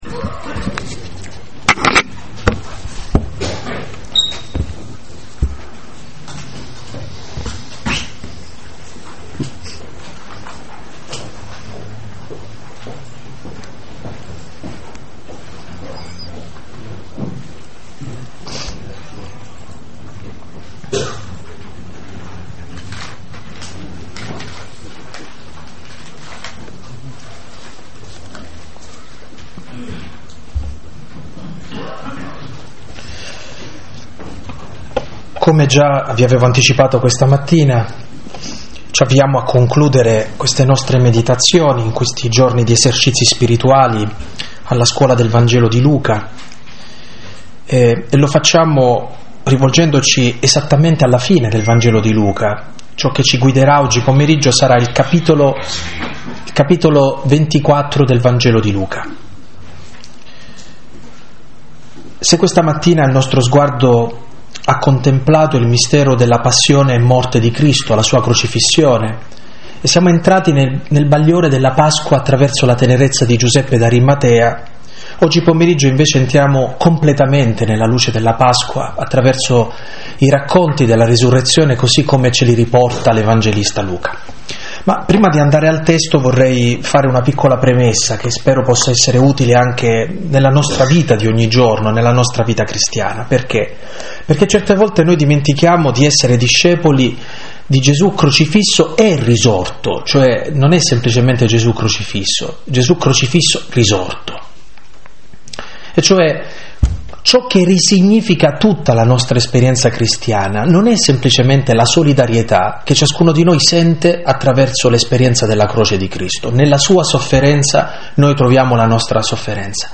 Meditazioni